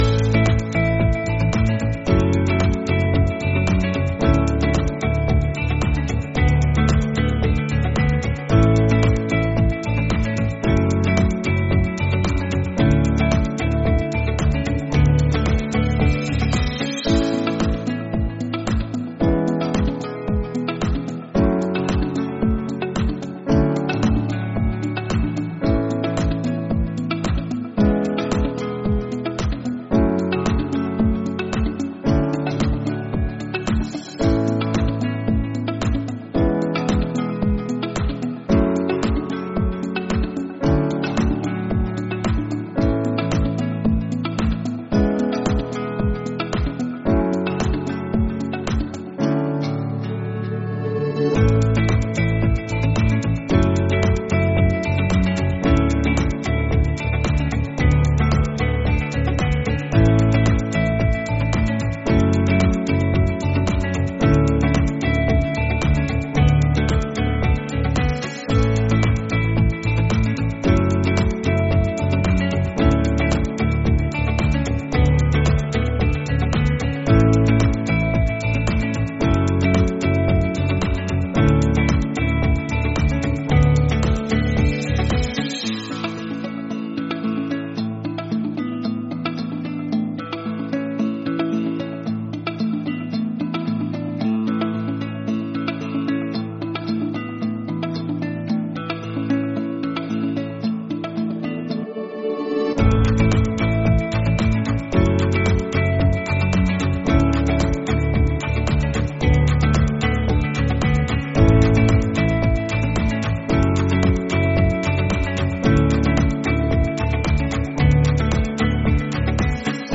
Música de transferència de trucada
PD: Estan molt comprimides perquè les pugueu escoltar.